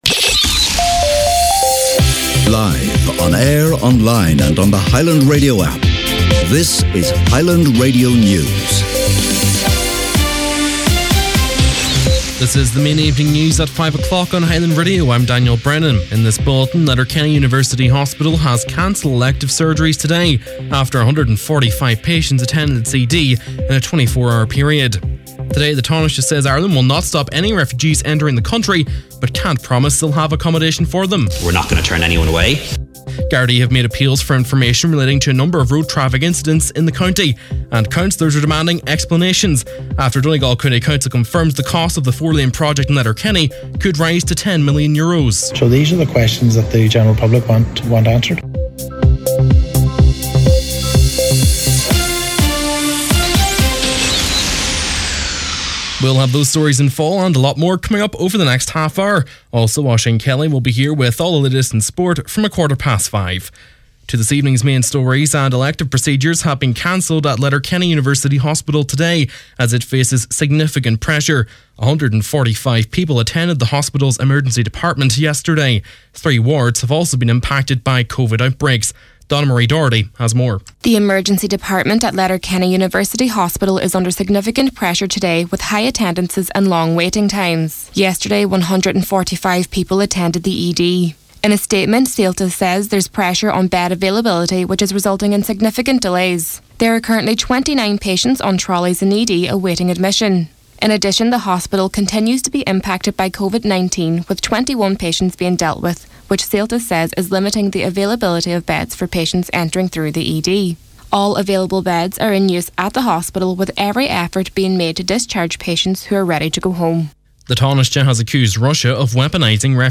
Main Evening News, Sport and Obituary Notices on Tuesday October 25th